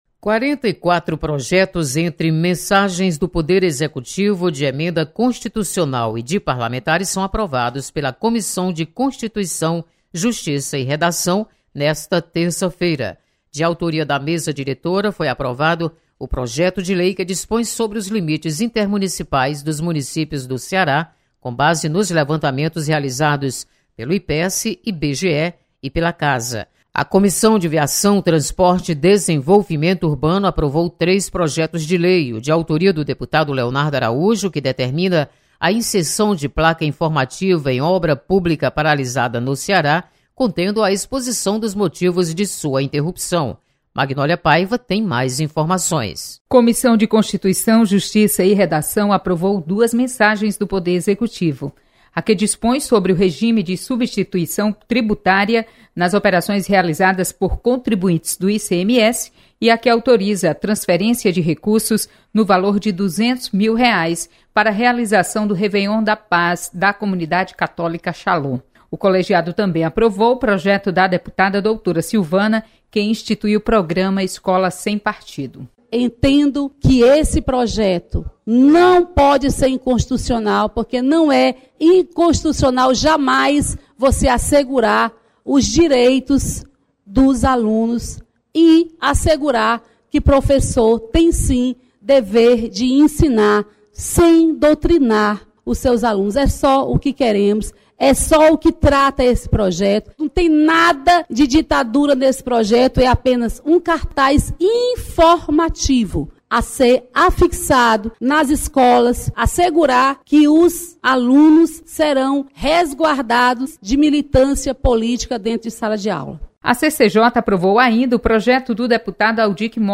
Mensagens do Poder Executivo são analisadas nas Comissões Técnicas. Repórter